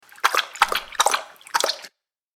Dog Drinking Water Sound Button - Free Download & Play